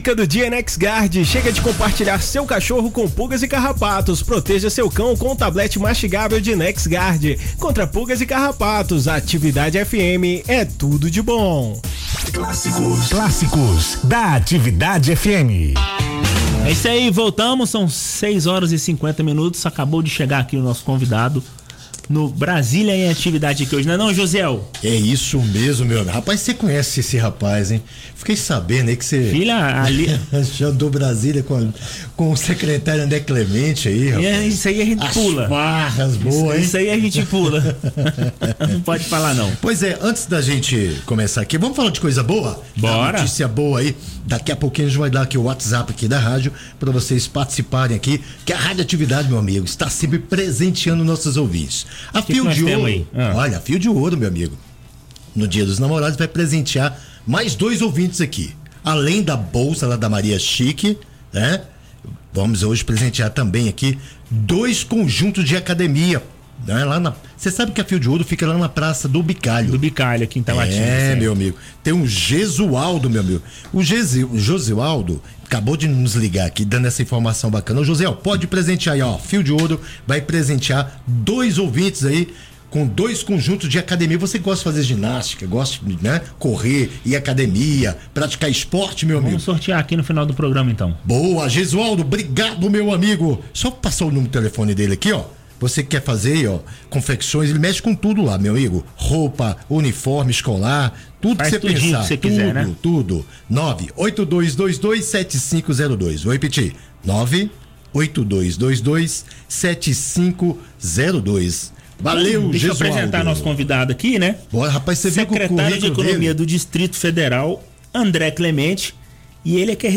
Clemente concede entrevista na Atividade, confira o áudio
O secretário de Economia do Distrito Federal, André Clemente, concedeu entrevista ao quadro Atividade Brasília do programa Clássicos da Atividade na Arividade FM ao vivo.